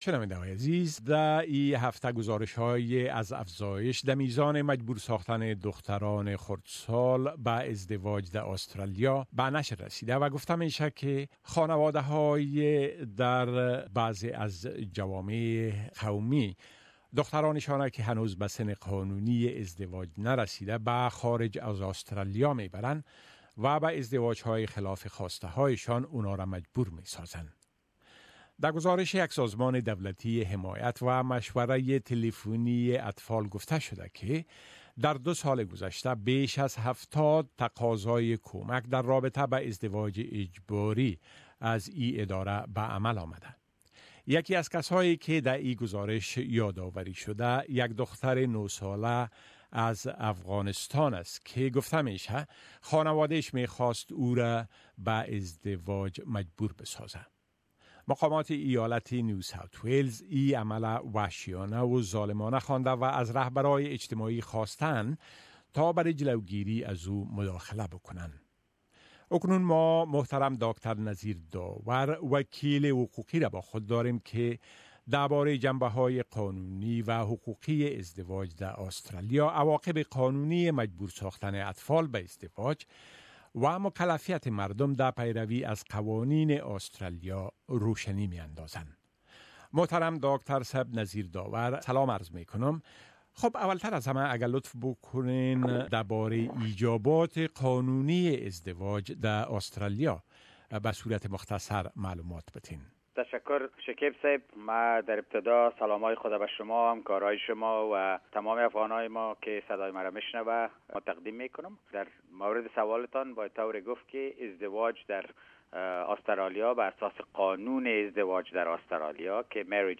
صحبت